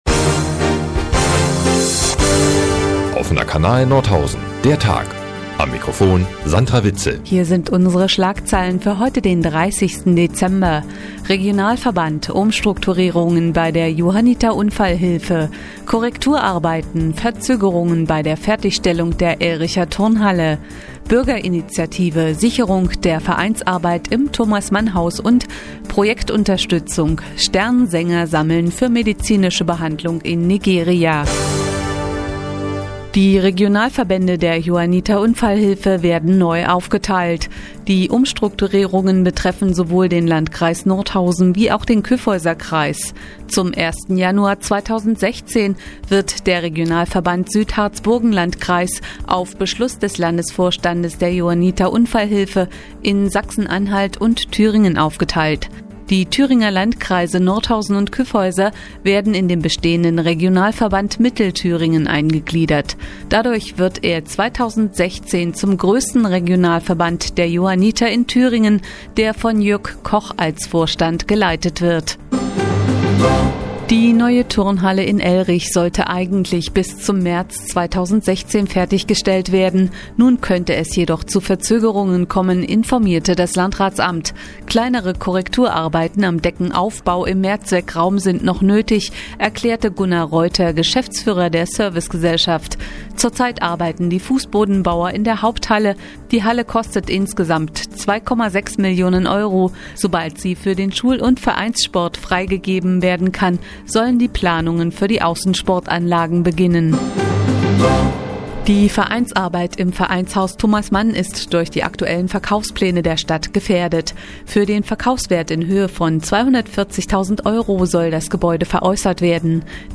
Die tägliche Nachrichtensendung des OKN ist jetzt hier zu hören...